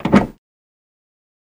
BMW Car Door Open